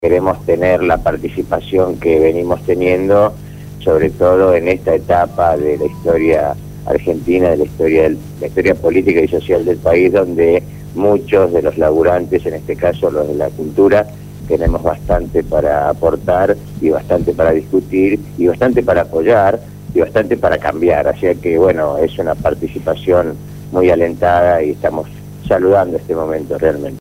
La  entrevista fue realizada en el programa Punto de Partida de Radio Gráfica FM 89.3